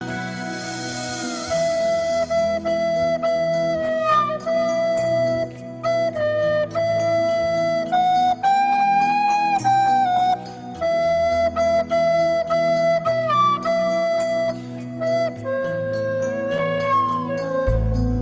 Звук флейты
искаженный кавер